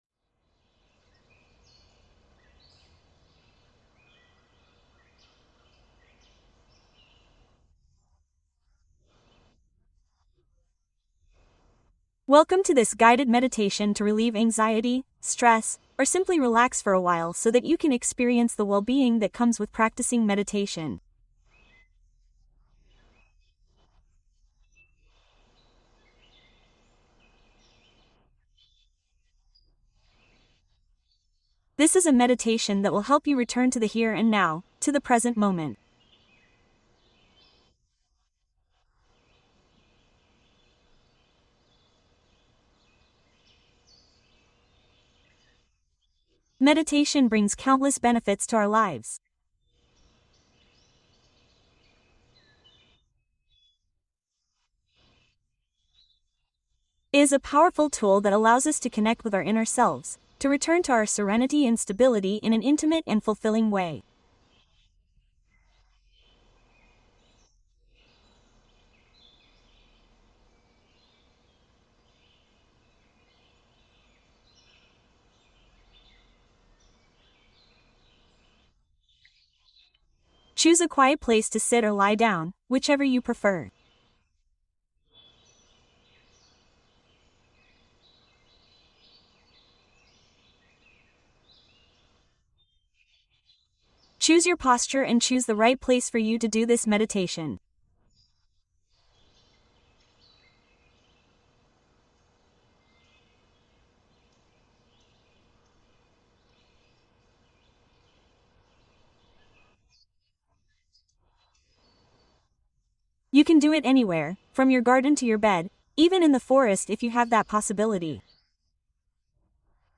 Clase completa de meditación | Respiración consciente y calma profunda